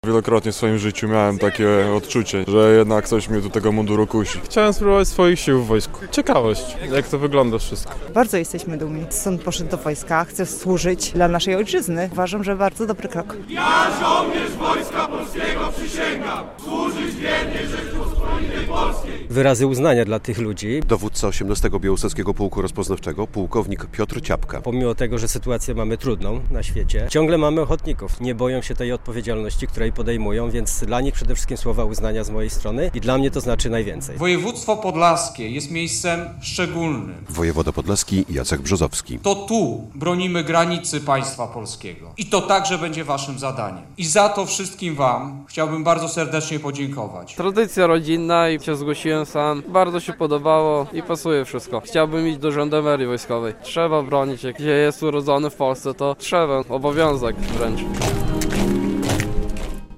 Blisko 50 żołnierzy dobrowolnej zasadniczej służby wojskowej złożyło na Placu Marszałka Józefa Piłsudskiego w Białymstoku uroczystą przysięgę.
relacja